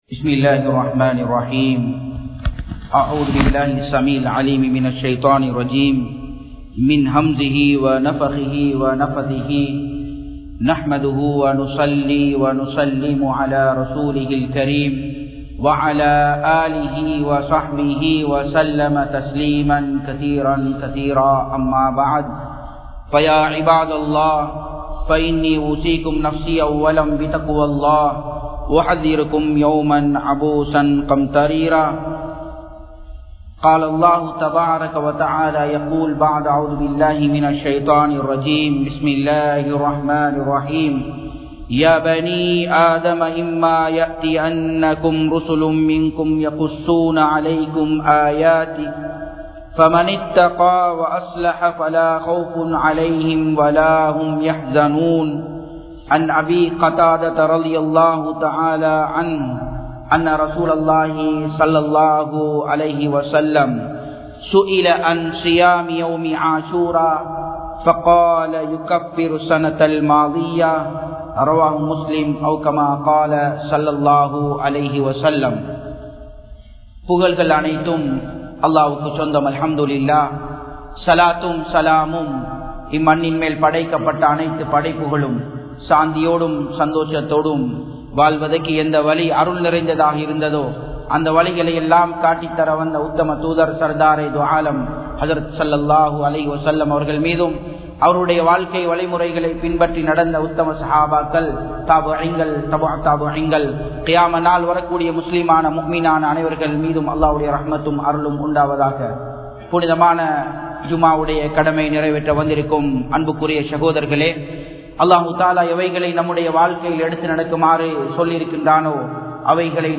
Aashura Noanpin Sirappuhal (ஆசுரா நோன்பின் சிறப்புகள்) | Audio Bayans | All Ceylon Muslim Youth Community | Addalaichenai
Japan, Nagoya Port Jumua Masjidh 2017-09-29 Tamil Download